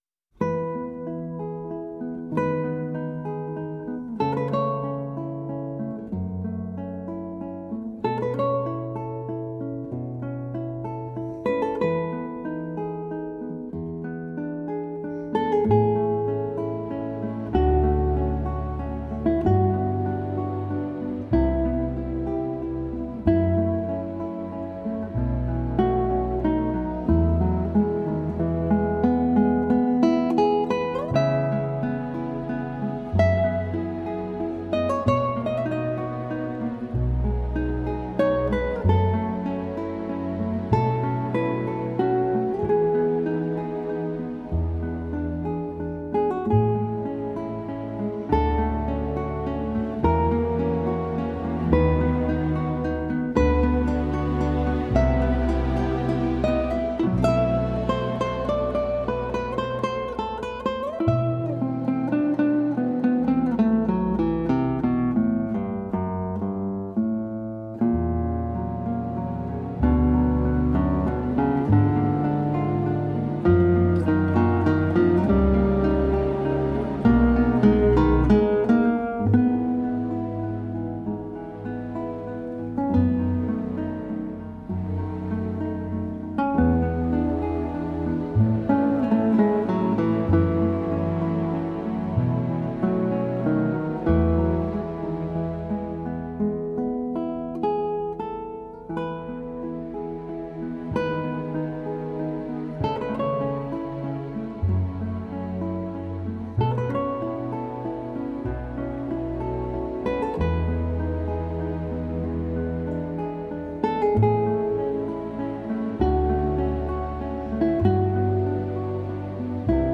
悠扬的吉他曲子响起，听起来暖洋洋的，仿佛为这寒冷的冬天带来了一丝春的气息。